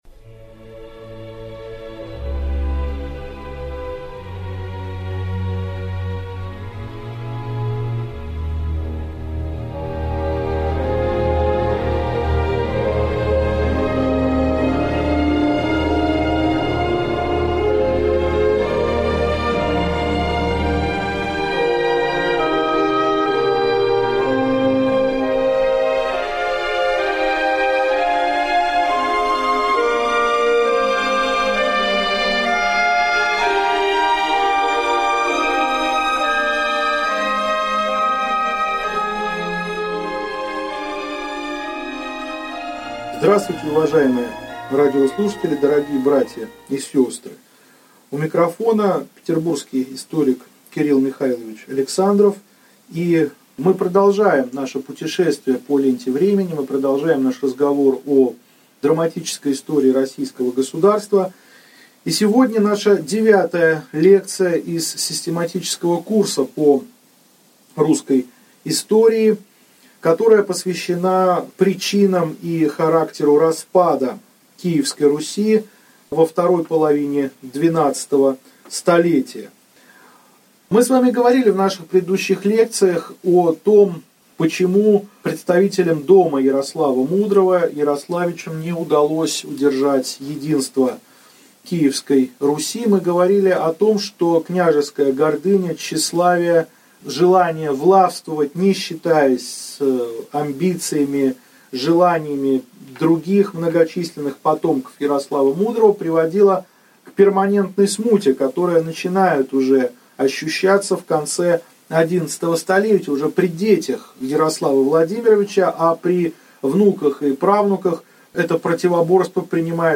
Аудиокнига Лекция 9. Причины распада Киевской Руси | Библиотека аудиокниг